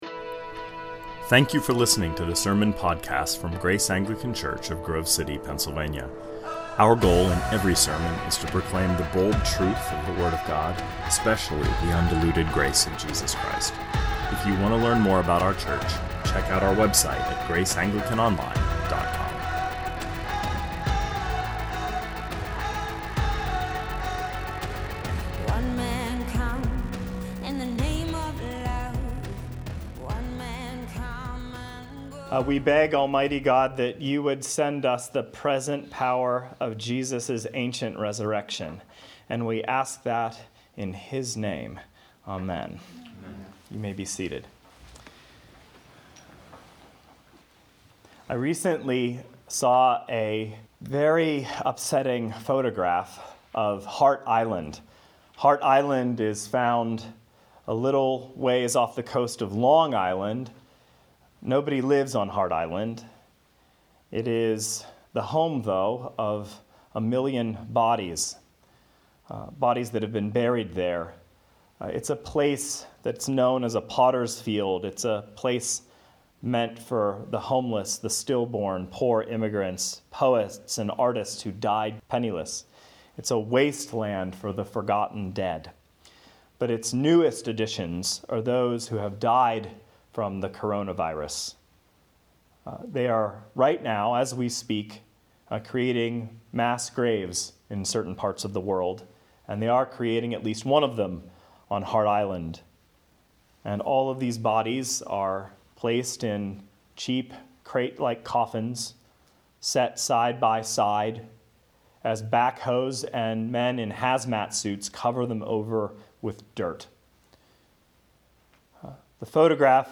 2020 Sermons Hart Island will Rise -An Easter Reflection Play Episode Pause Episode Mute/Unmute Episode Rewind 10 Seconds 1x Fast Forward 30 seconds 00:00 / 26:00 Subscribe Share RSS Feed Share Link Embed